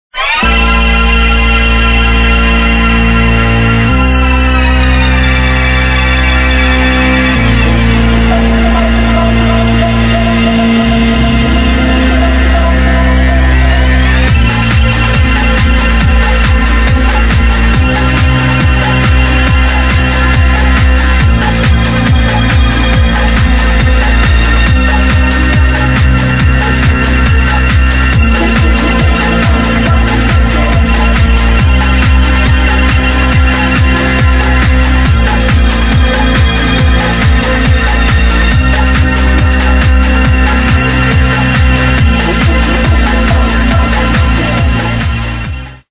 techno track
WMC 2003